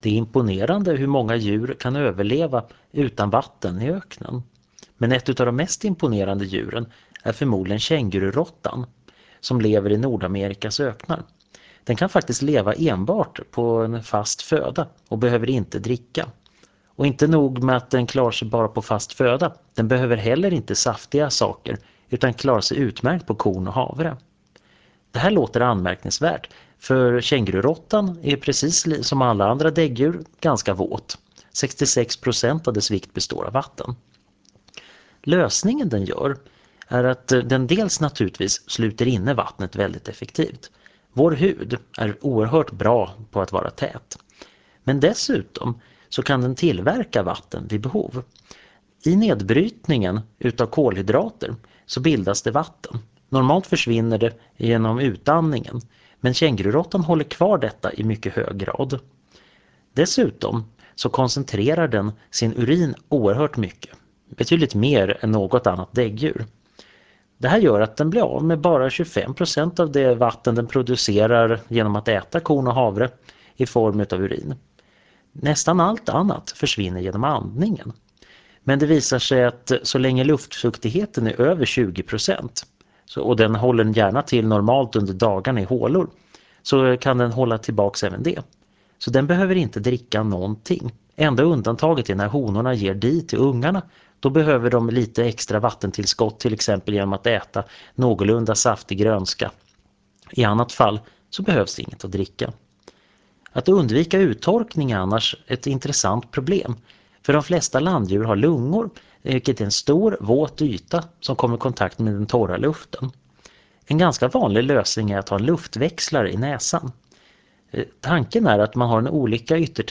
Föredraget handlar om Biologi och sänds 2001-09-23 i Förklarade_fenomen.